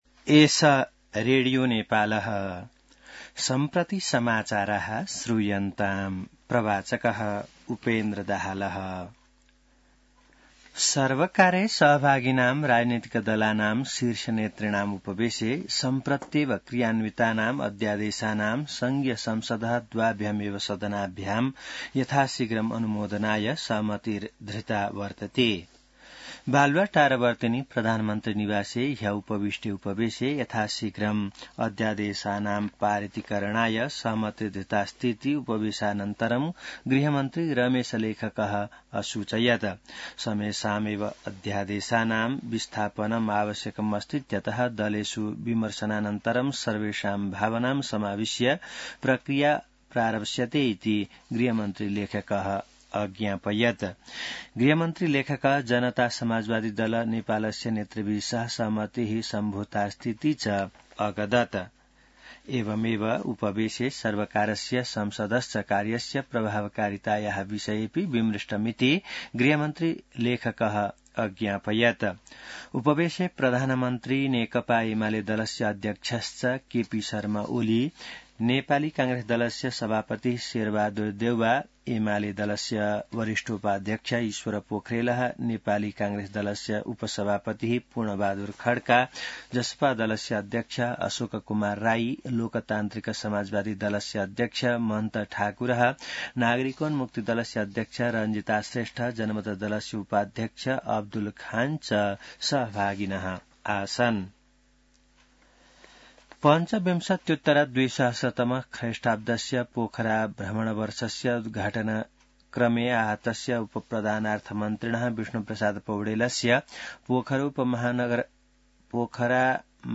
संस्कृत समाचार : ५ फागुन , २०८१